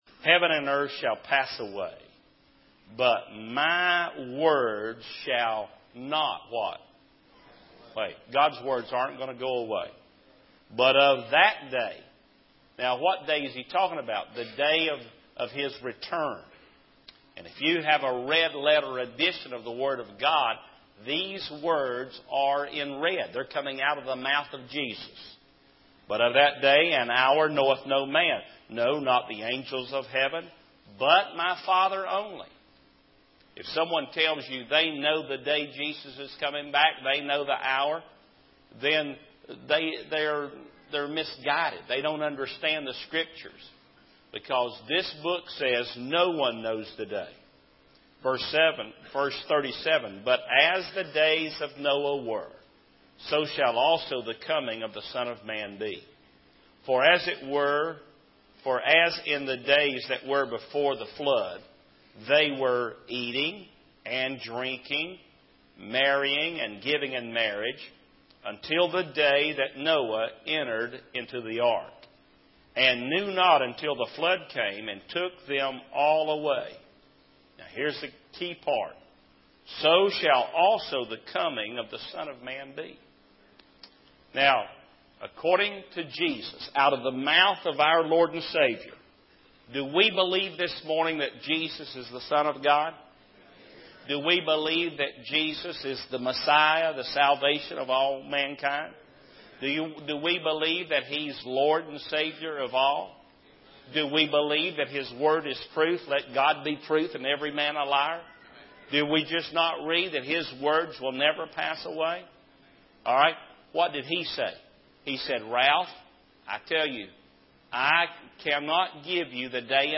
In this sermon, the minister discusses the alarming events of violence and terror in society, such as the kidnappings and murders of young children and the school shootings. He emphasizes the importance of being aware of the signs of the end times, as Jesus warned in Matthew 24. The minister suggests studying the days of Noah in Genesis 4, 5, and 6 to understand the parallels between that time and the present day.